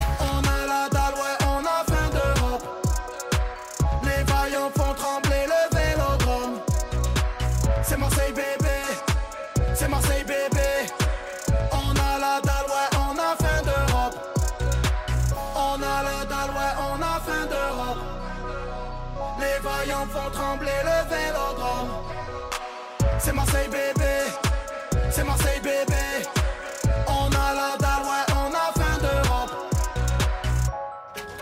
Hip Hop ,Pop